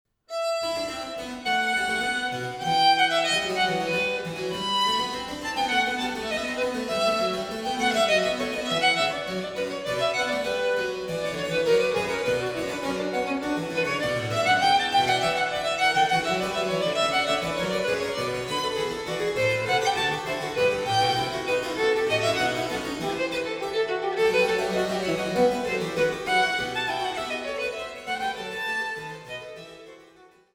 Tasteninstrumente
Barockvioline
Cello